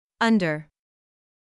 音声学的には、全て「鼻音（びおん）」に分類され、鼻から息を抜くように発音するのが特徴です。